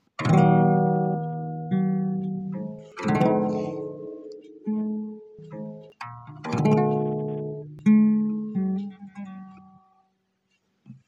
• Flat-to-Flat: A flush connection indicates a Consonant interval (a 3rd or a 5th).
• Point-to-Point: Touching only at the vertex indicates a Dissonant interval (a 2nd or 7th).
qard-diss.mp3